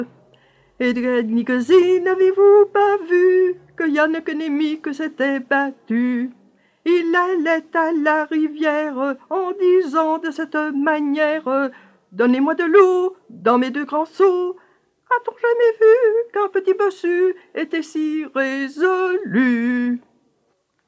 Genre : chant
Type : chanson d'enfants
Interprète(s) : Anonyme (femme)